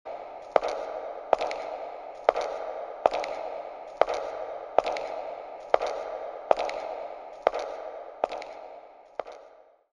Heels Clicking Legacies Téléchargement d'Effet Sonore